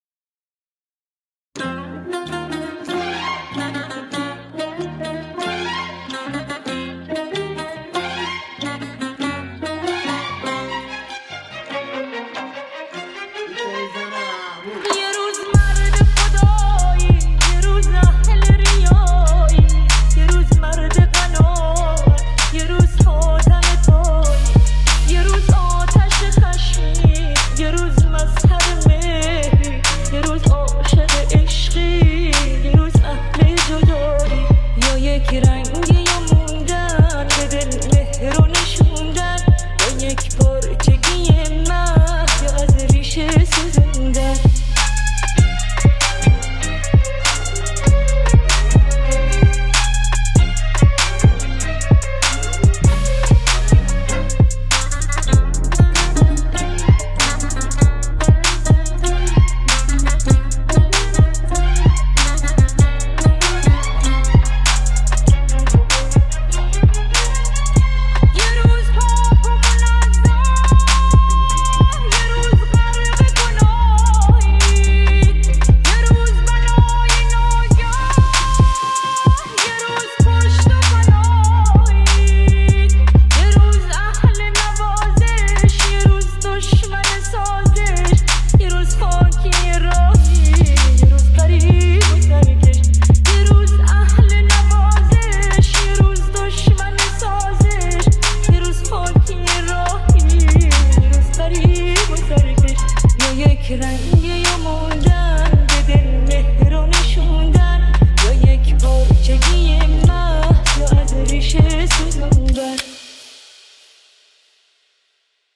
ریمیکس رپ بیس دار